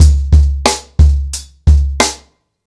• 90 Bpm Drum Beat C# Key.wav
Free drum groove - kick tuned to the C# note. Loudest frequency: 1251Hz
90-bpm-drum-beat-c-sharp-key-j7p.wav